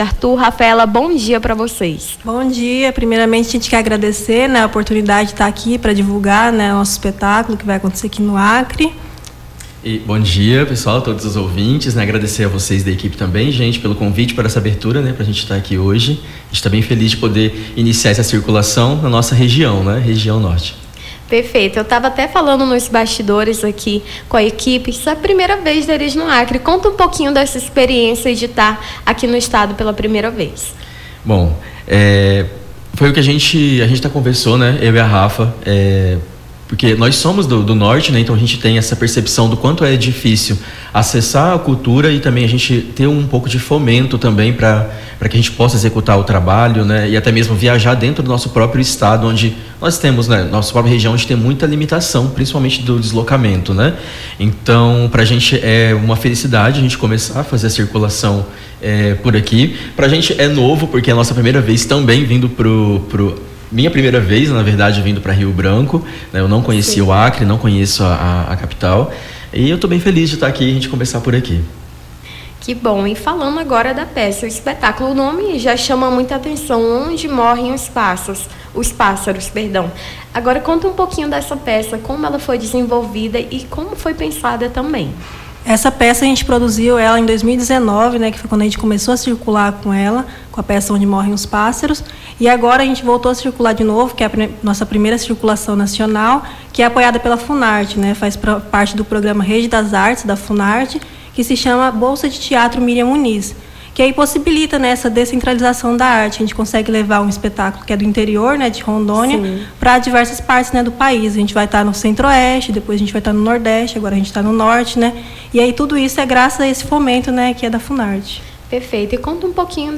Nome do Artista - CENSURA - ENTREVISTA (ESPETACULO ONDE MORA OS PASSAROS) 23-04-25.mp3